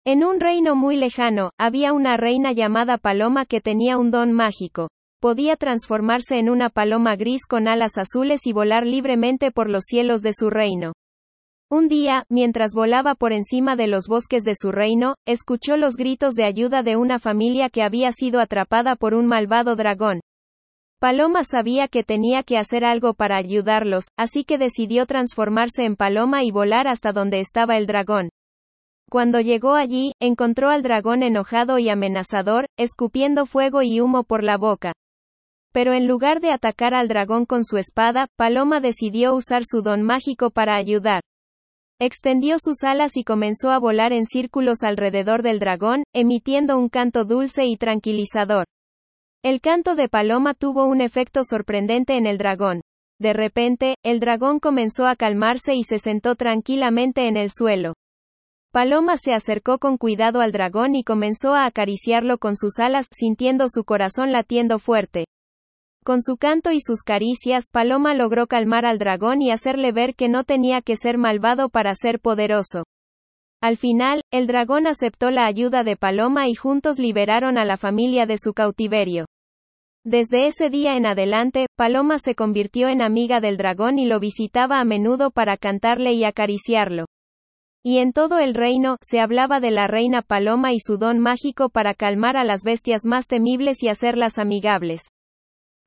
¡Aquí tienes el audio cuento!